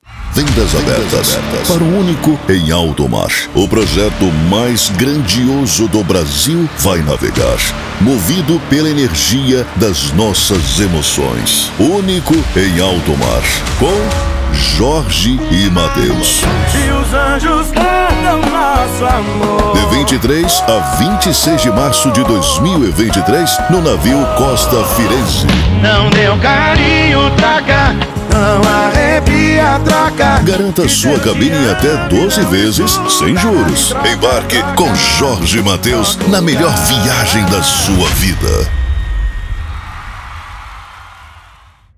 Spot Comercial
Chamada de Festa
Animada